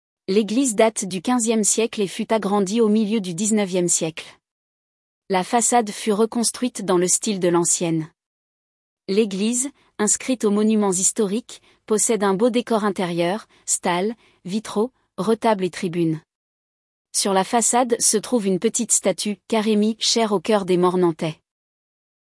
audio guide de l'Eglise